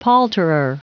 Prononciation du mot palterer en anglais (fichier audio)
Prononciation du mot : palterer